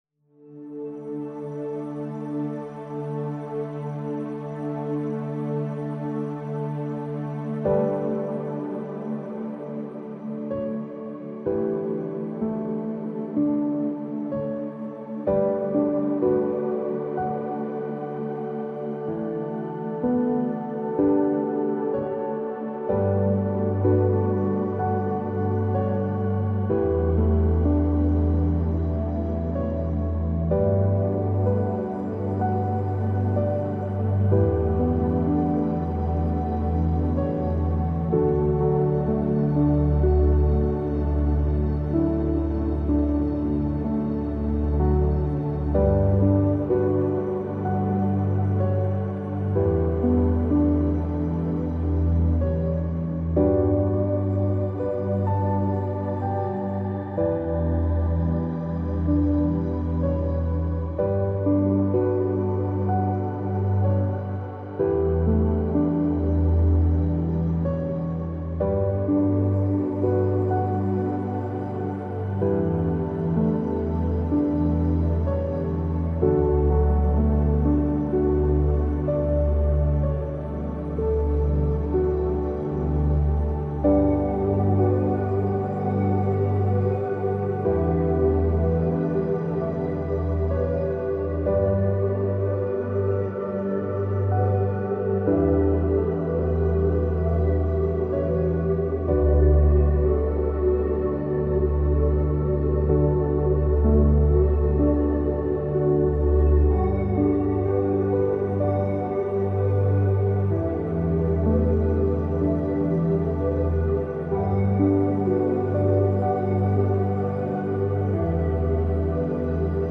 13hz - Alpha Binaural Beats for Chill ~ Binaural Beats Meditation for Sleep Podcast
Mindfulness and sound healing — woven into every frequency.